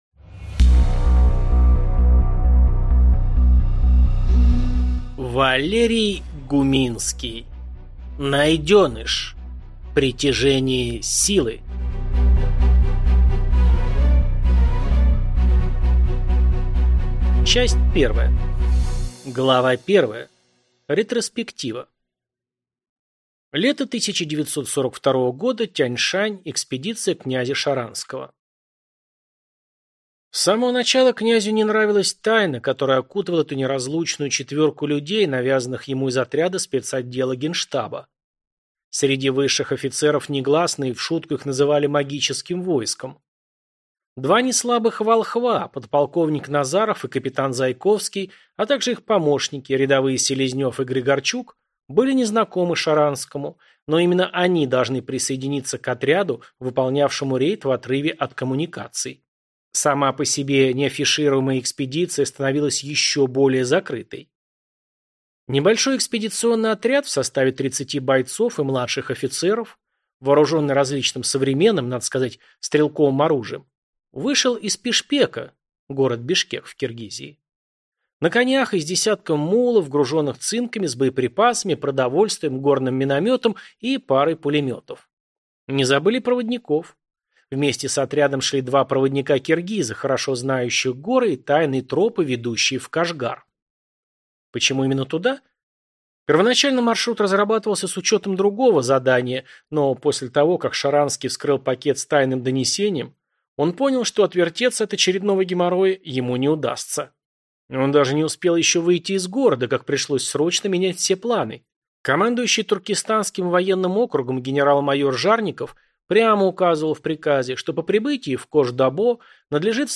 Аудиокнига Найденыш. Притяжение Силы | Библиотека аудиокниг